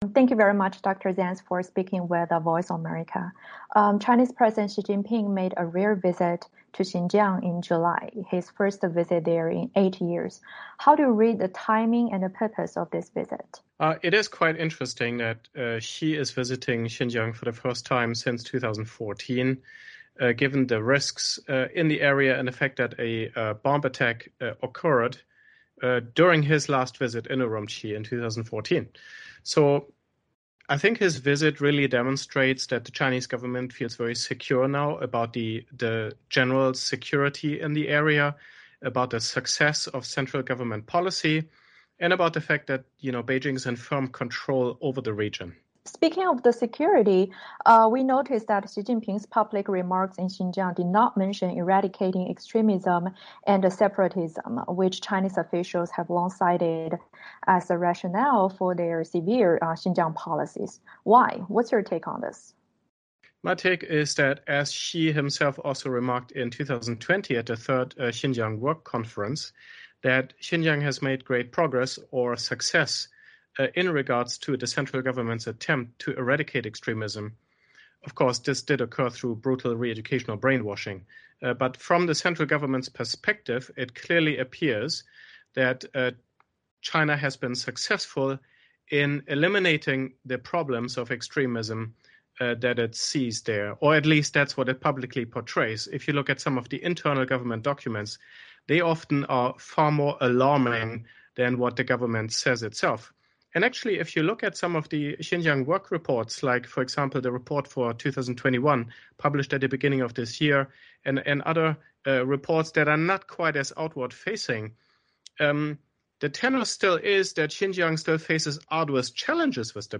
新疆人权问题知名学者郑国恩在接受美国之音专访时表示，中国政府的新疆政策已经发生转向，国际压力将持续发挥作用。